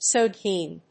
音節soi・gné 発音記号・読み方
/swɑːnjéɪ(米国英語), ˈswɑː.njeɪ(英国英語)/